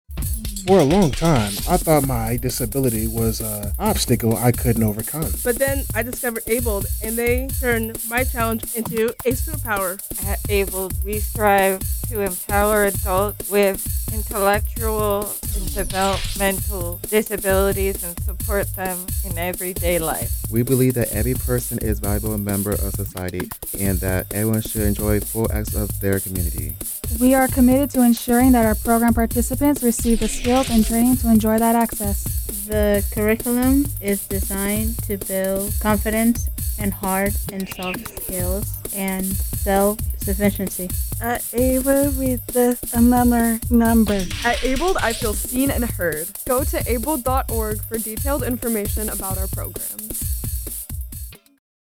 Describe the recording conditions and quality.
Check out ABLED's PSA, recorded at iHeartRadio Studios!